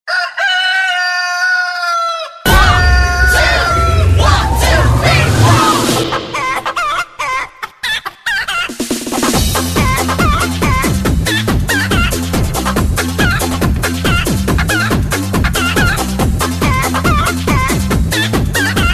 Categoria Animali